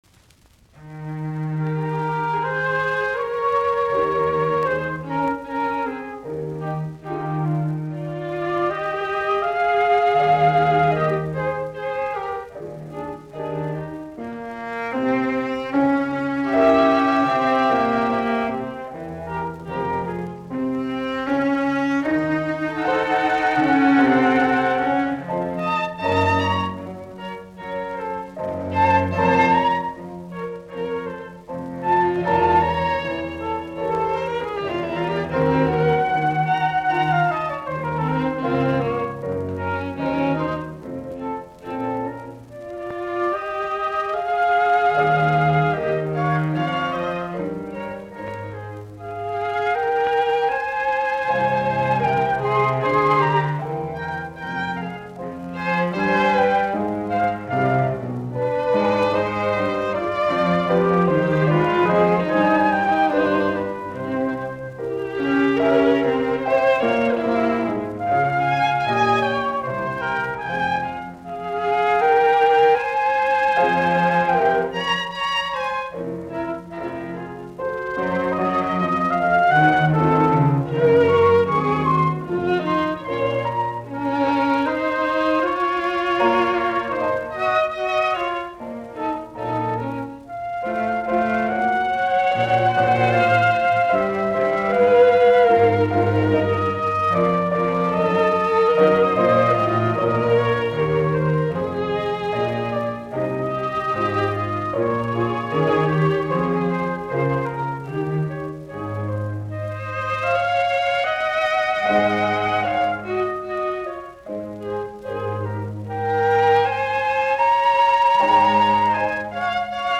Largo - Allegro
Soitinnus: Piano.